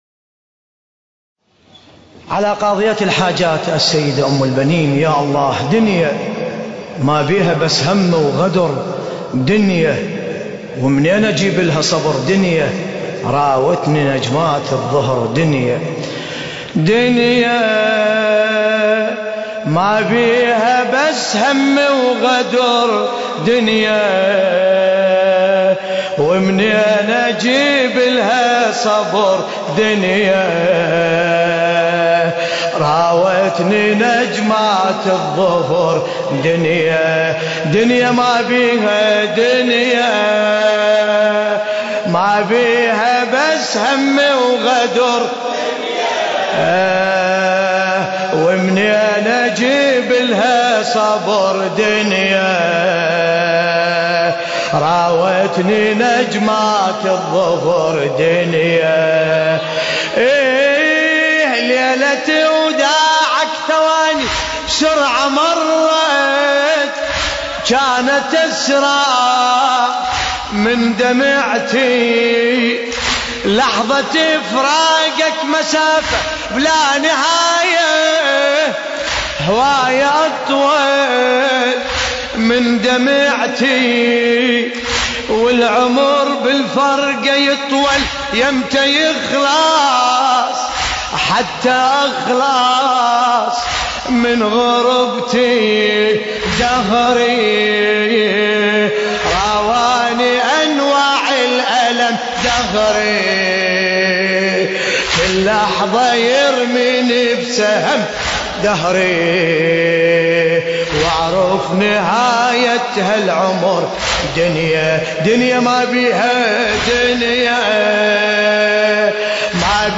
القارئ: باسم الكربلائي التاريخ: ليلة4 محرم الحرام 1434 هـ - مسجد أبو الفضل العباس عليه السلام - الكويت.